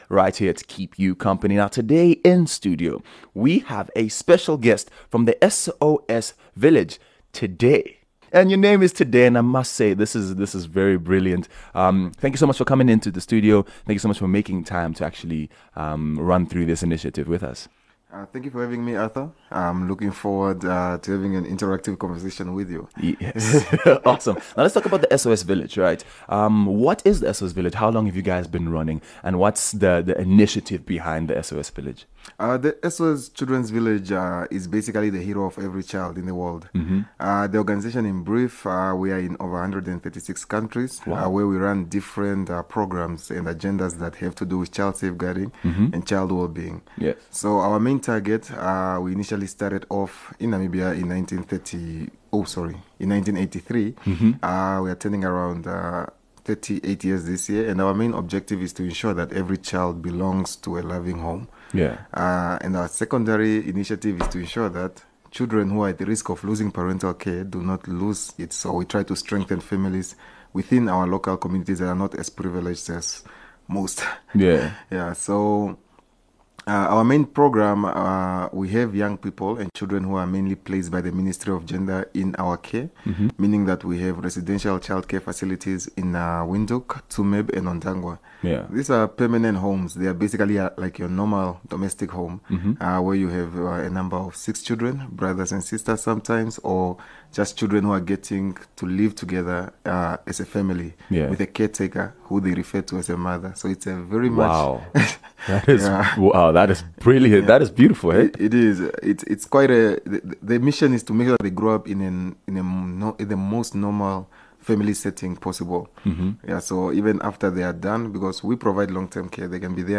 Quick chat